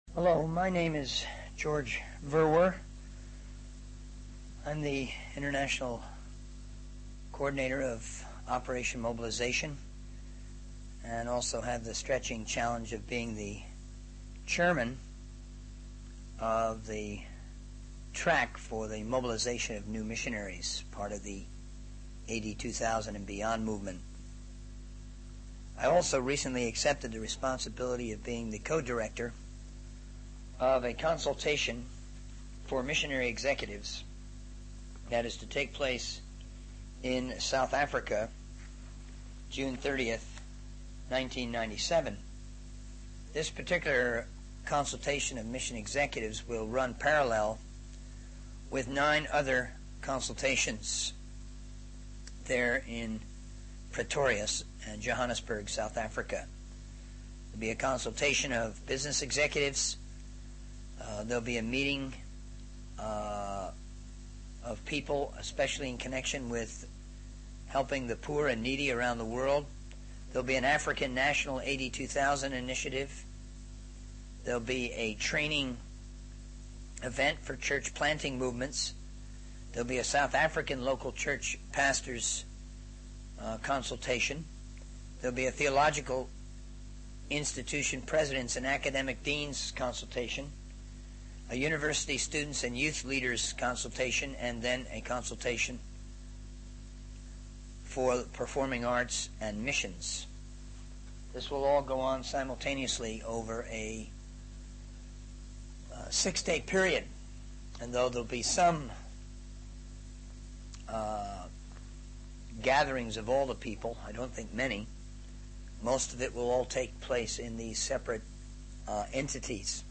In this sermon, the speaker discusses the importance of new emerging missionaries from sending countries in evangelizing the world. They emphasize the need for more research, listening to one another, and being open to change in order to effectively spread the gospel. The speaker also mentions the tension between short-term and long-term missions and how this will be addressed during a six-day congress.